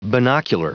Prononciation du mot binocular en anglais (fichier audio)
Prononciation du mot : binocular